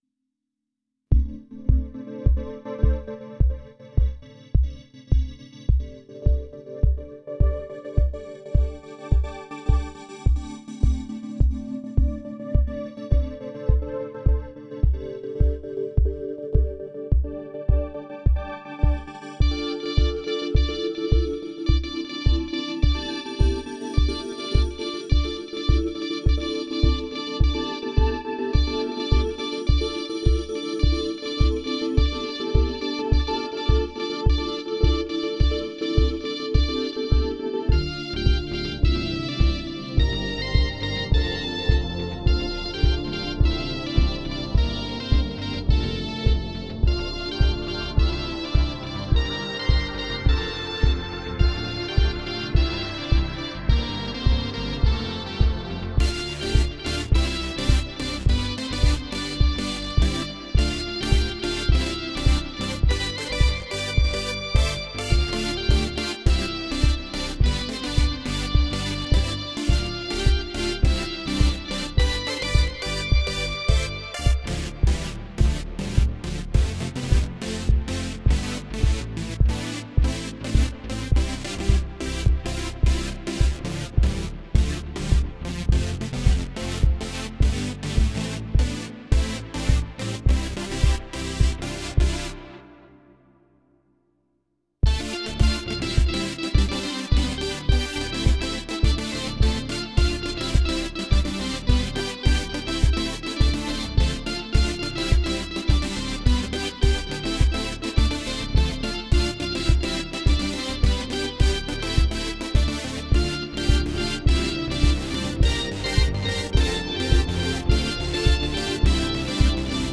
* headphones/earbuds only